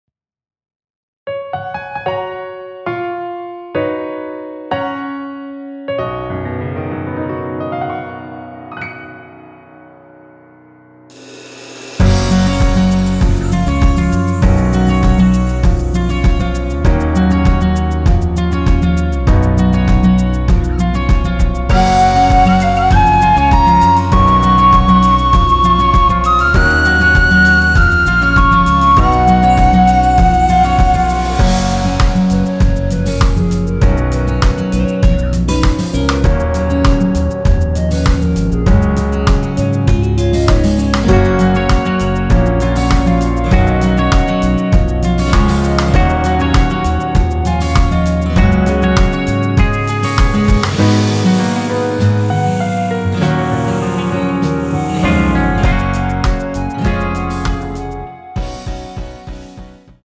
원키에서(-2)내린 (1절삭제) 멜로디 포함된 MR입니다.
F#
앞부분30초, 뒷부분30초씩 편집해서 올려 드리고 있습니다.